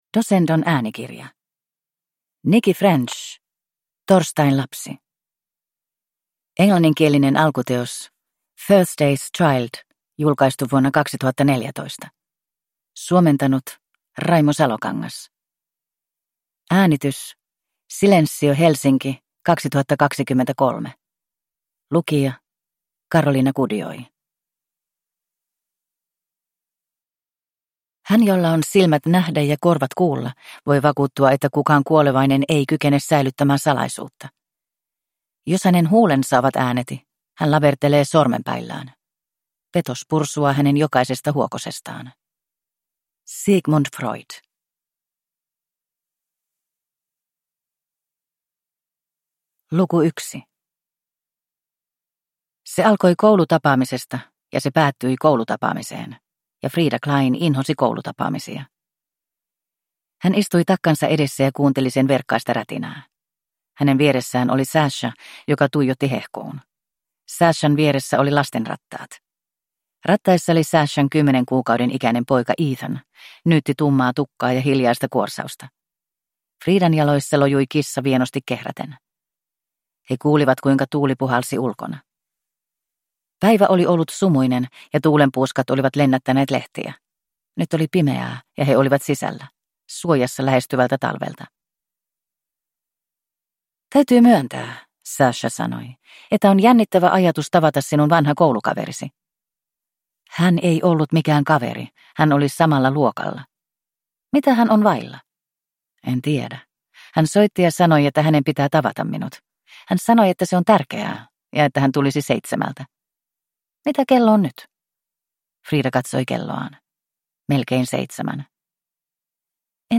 Torstain lapsi (ljudbok) av Nicci French